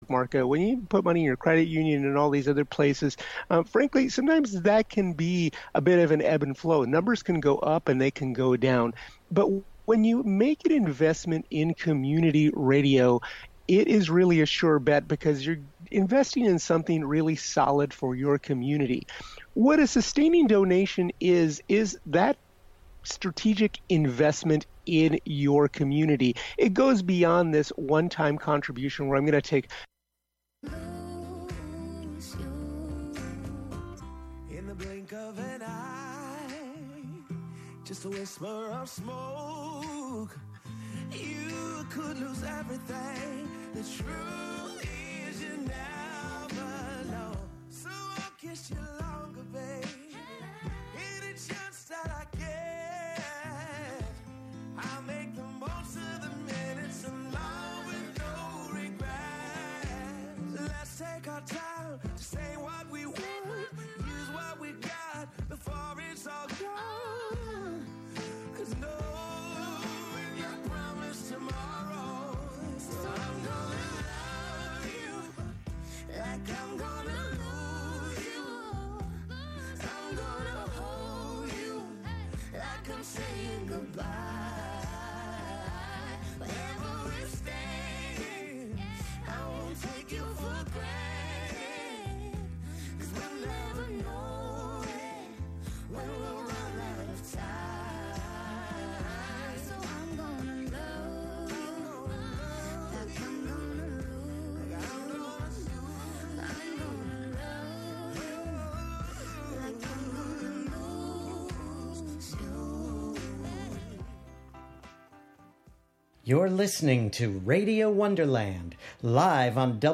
Live from Brooklyn, NY
play those S's, T's and K's like a drum machine